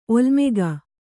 ♪ olmega